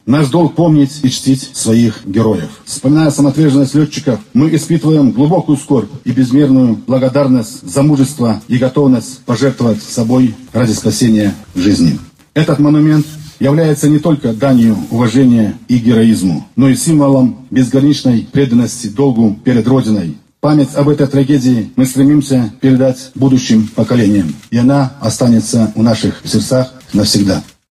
Накануне в Барановичах делегатов из разных районов Брестчины у мемориала в честь летчиков – героев майора Андрея Ничипорчика и лейтенанта Никиты Куконенко приветствовал  глава горсовета депутатов Виктор Колосовский.